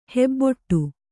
♪ hebboṭṭu